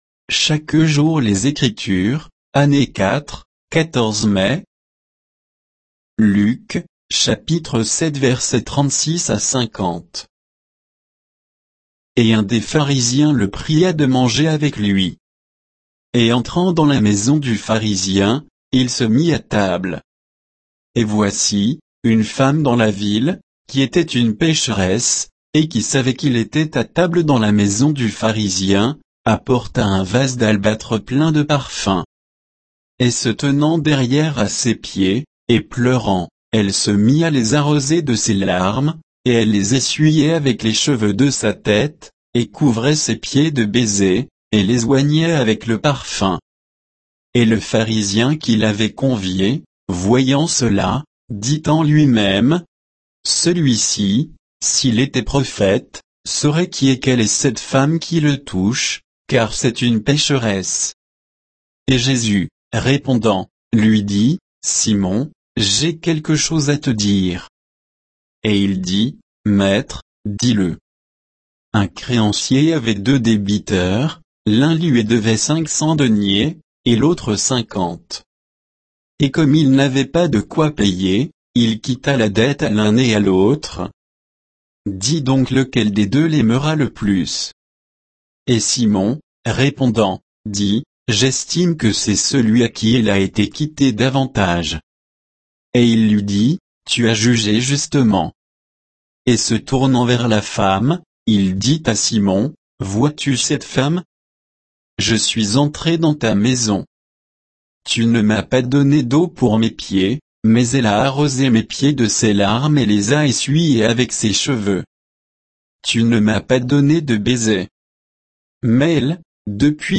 Méditation quoditienne de Chaque jour les Écritures sur Luc 7, 36 à 50